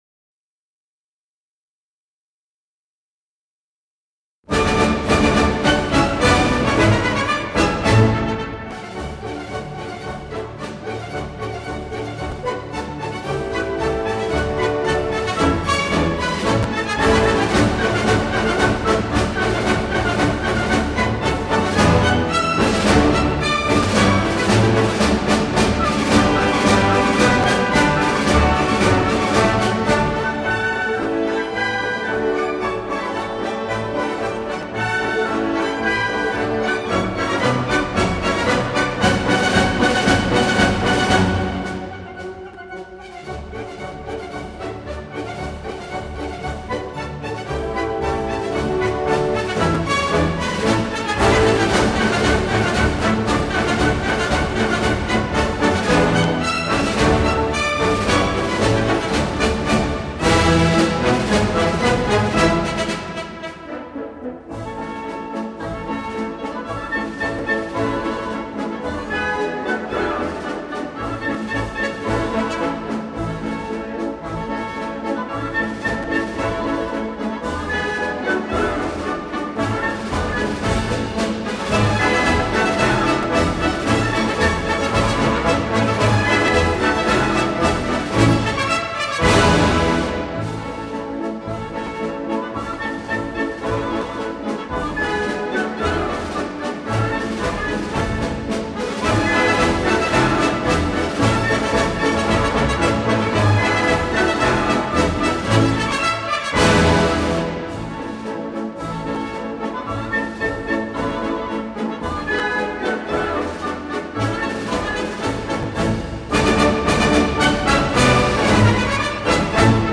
气势恢弘 振奋人心 场面浩大
节奏极富铿锵之力，音符掷地有声，威武雄壮之气一显无遗。